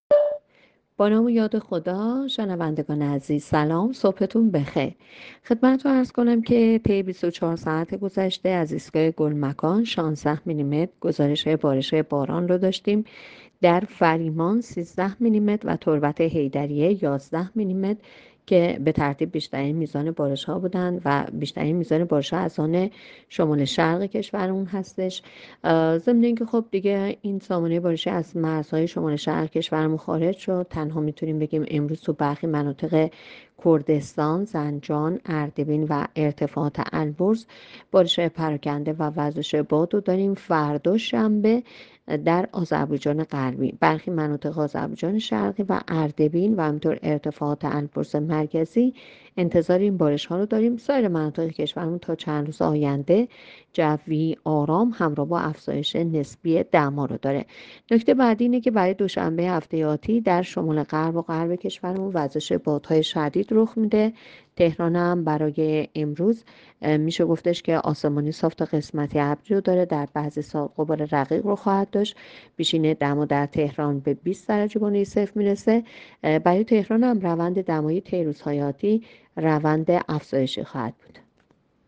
گزارش رادیو اینترنتی پایگاه‌ خبری از آخرین وضعیت آب‌وهوای ۸ فروردین؛